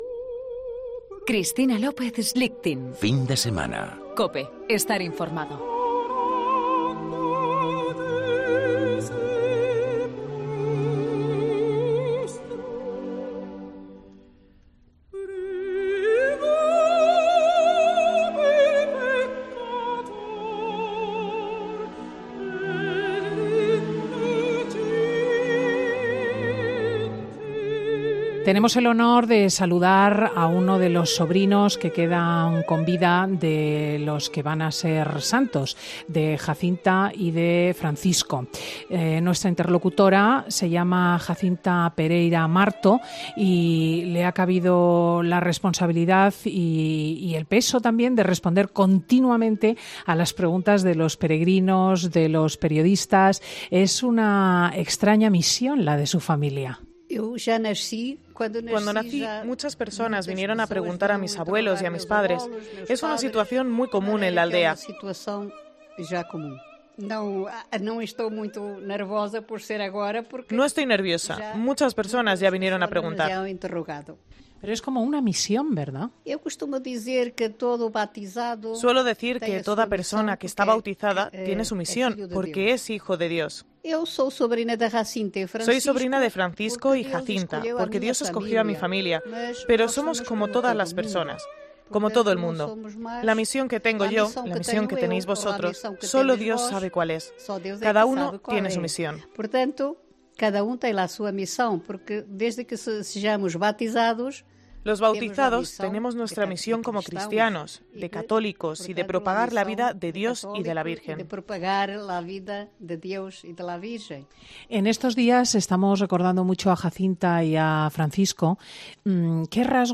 EN 'FIN DE SEMANA' DESDE FÁTIMA